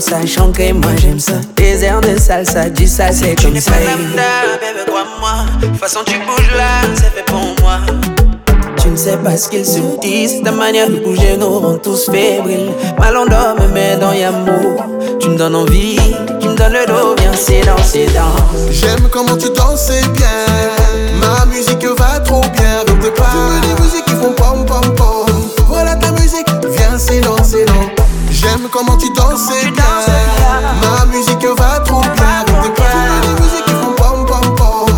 Afro-Beat, African